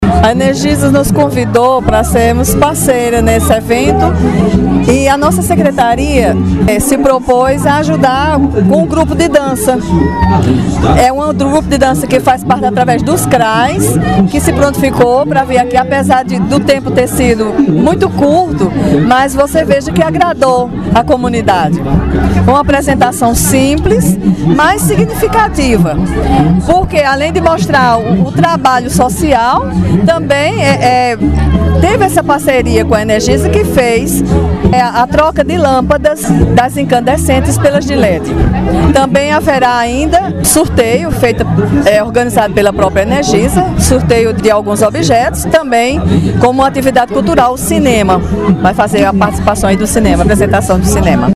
Fala da Secretária de Desenvolvimento Social, Maria da Guia Lustosa